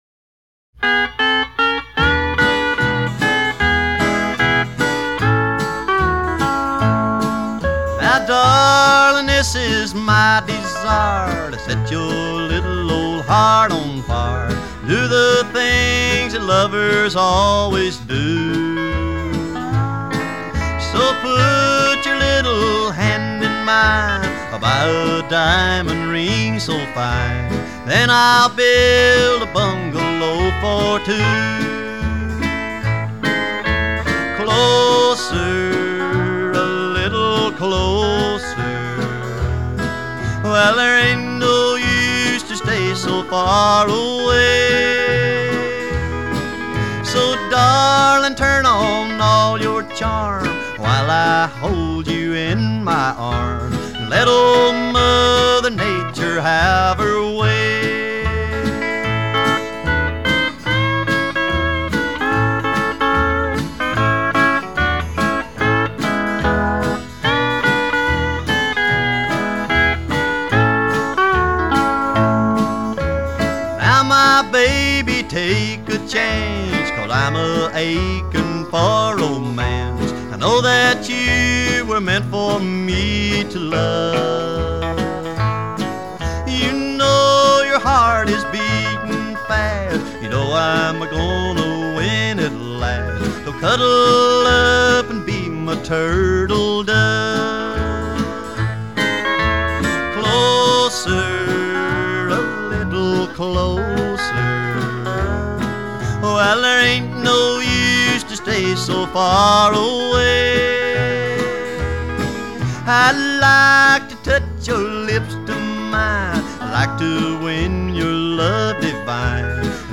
американский кантри-певец.